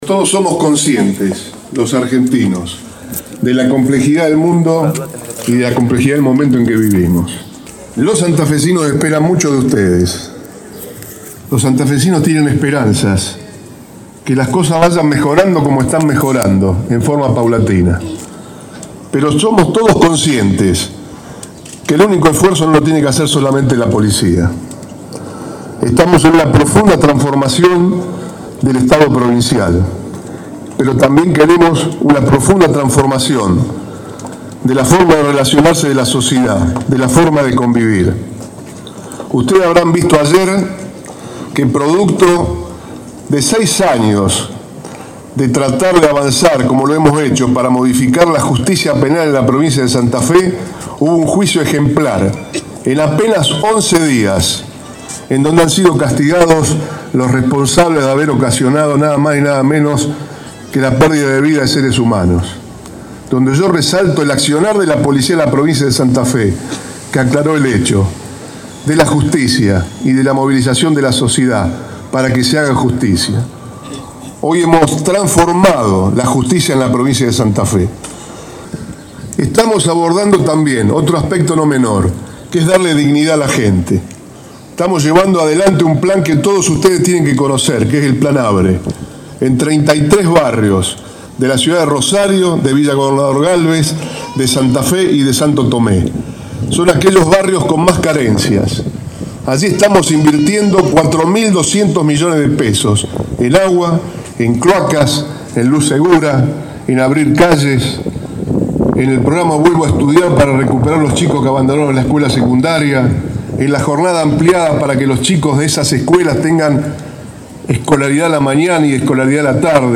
Justicia y Seguridad El gobernador y el ministro de Seguridad en el acto de egreso de 900 cadetes del Instituto de Seguridad Pública.